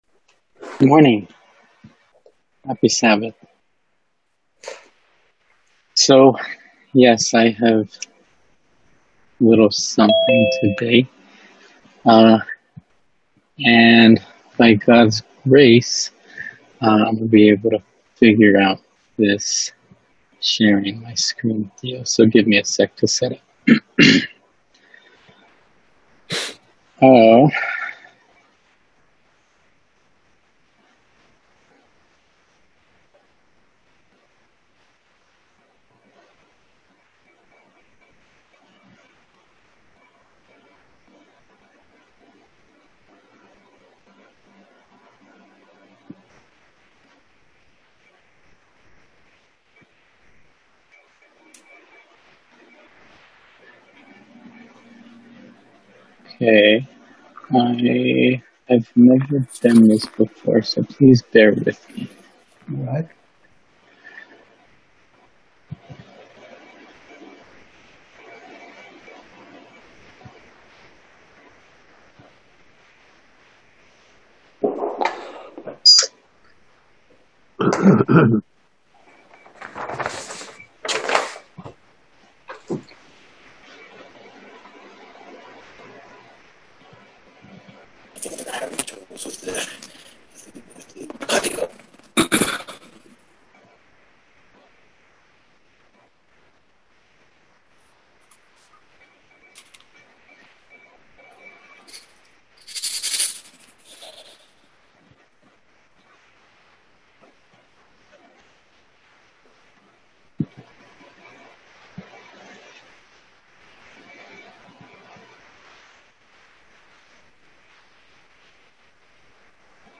F.O.F on 2020-08-29 - Sermons, 2020